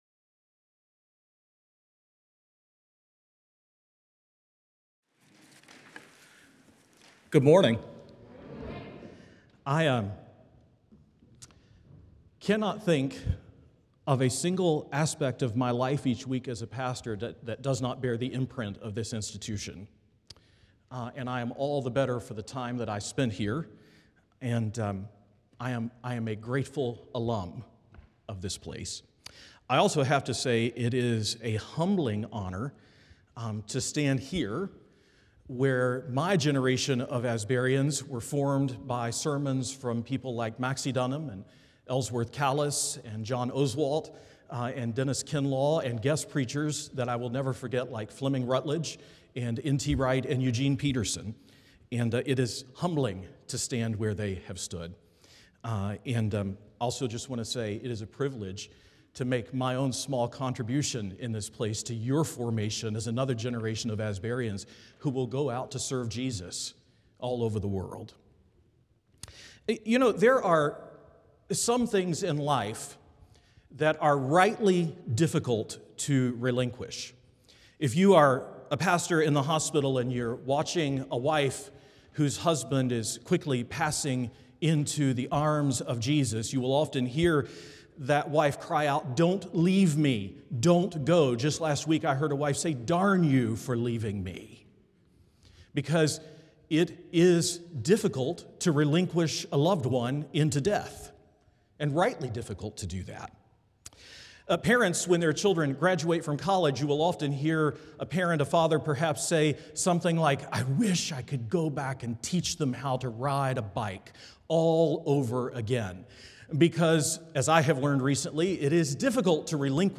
The following service took place on Thursday, April 3, 2025.